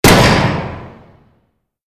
LB_camera_shutter_2.ogg